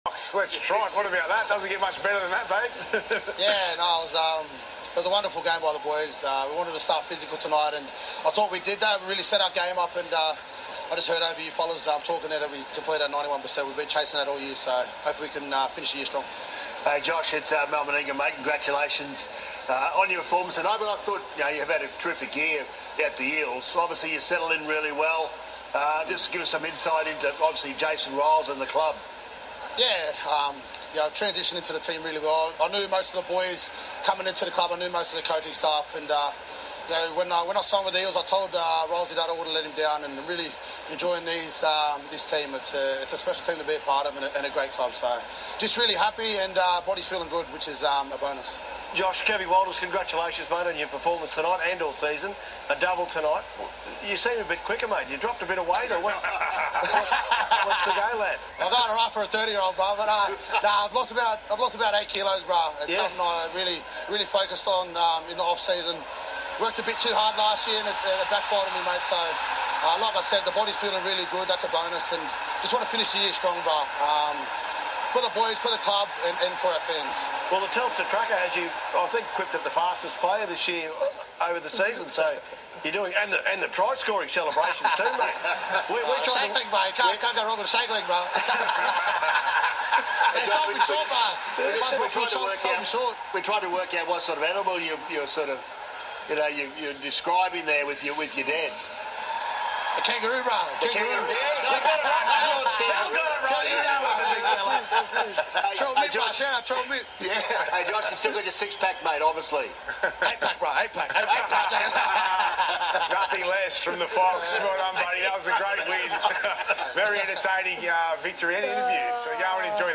Josh Addo-Carr post match interview!